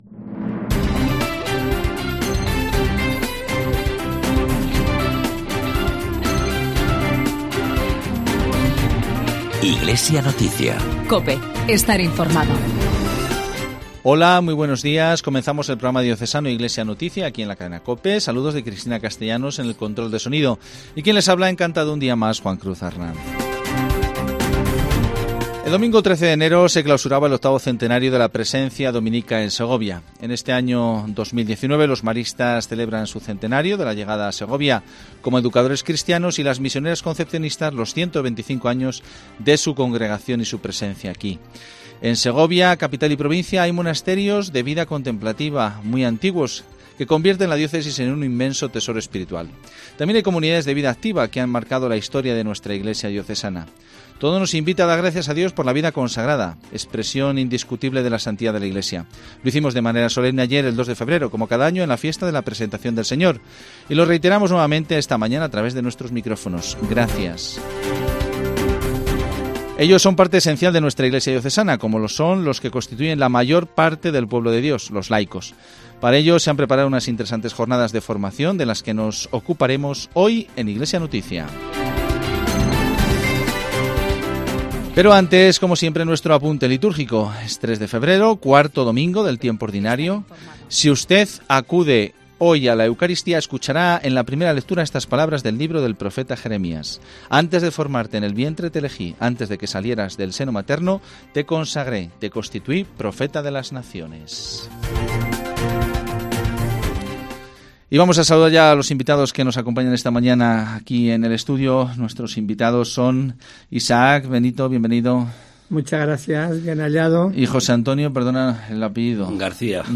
Programa semanal de información cristiana